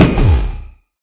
sounds / mob / enderdragon / hit2.ogg